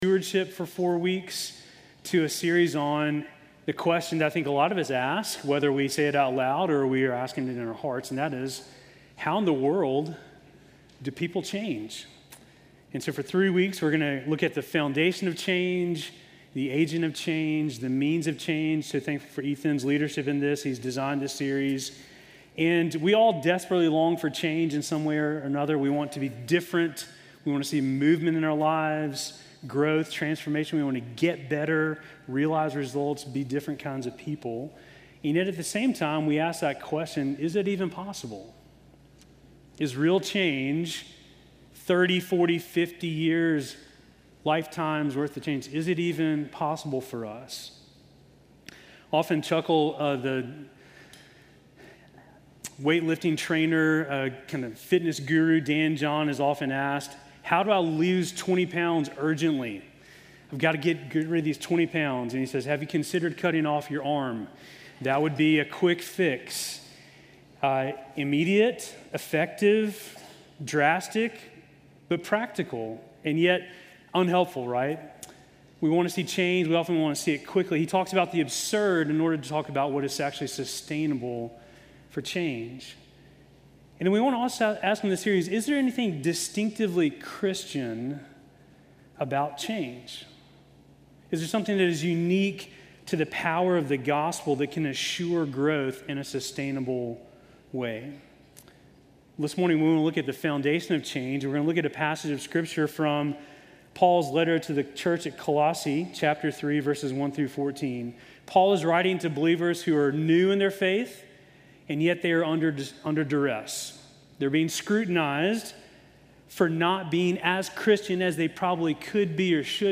Sermon from July 27